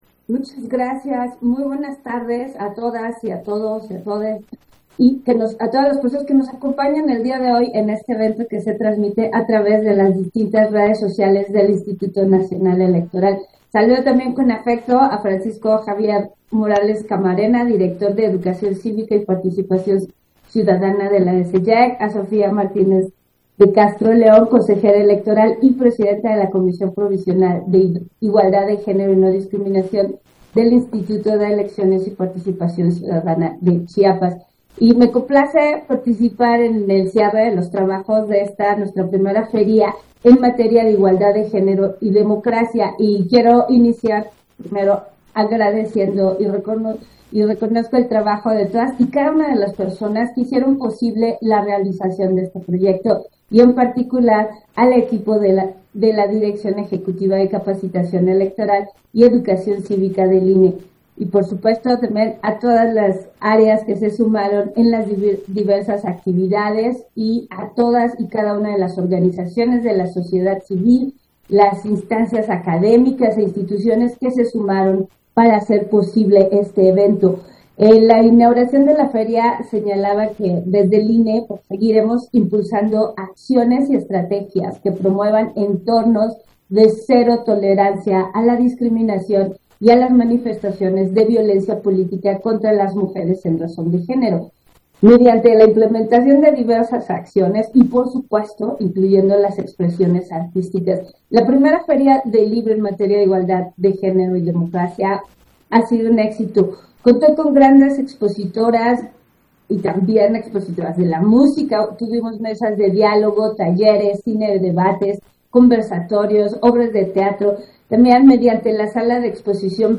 Intervención de Norma de la Cruz, en la clausura de la 1era. Feria del libro INE, Igualdad de Género y Democracia